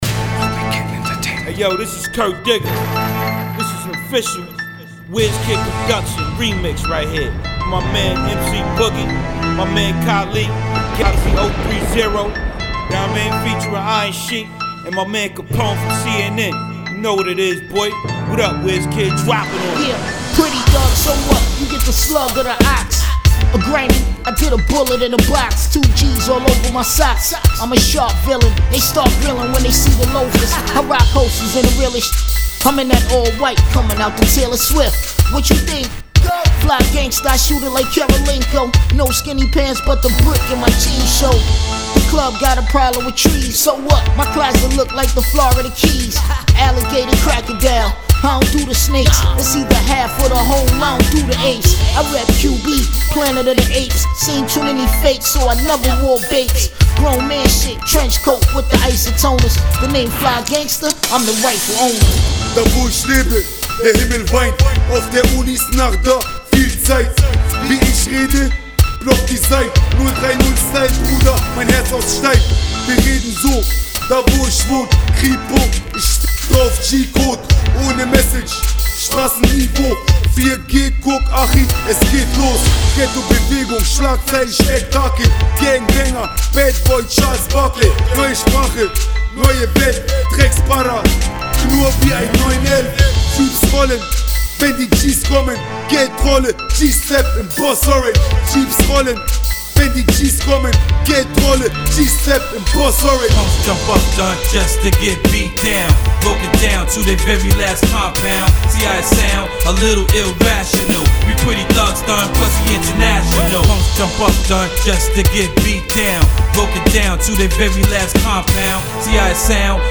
international remix
Street Rap